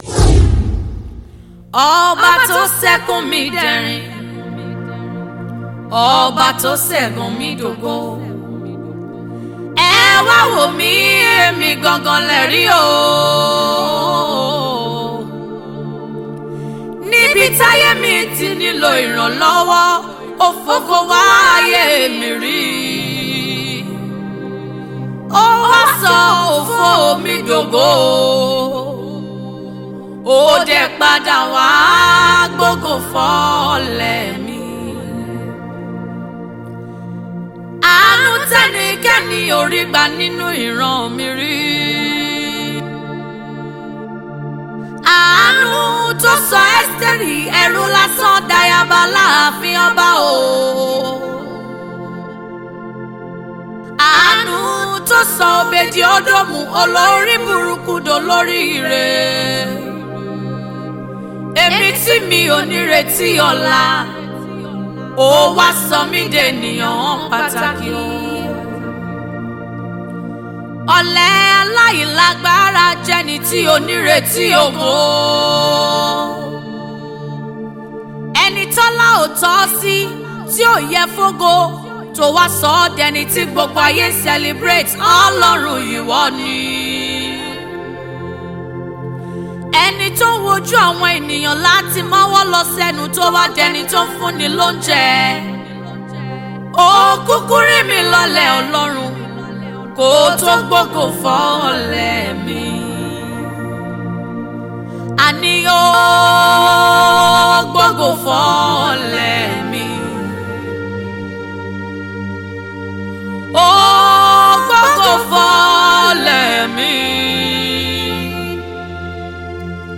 Indigenous Nigerian Gospel Singer
Yoruba eulogy of God
energetic vocal range and lively charisma